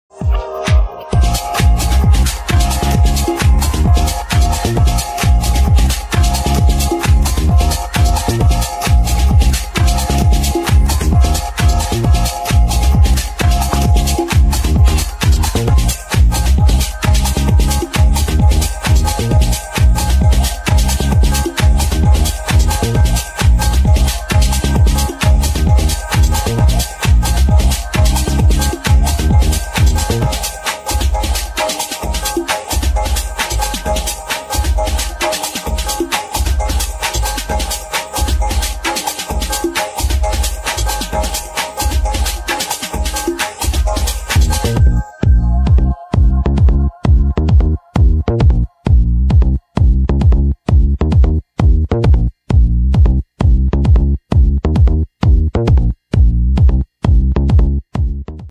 Classic, Vintage Detroit Dance Remix Music.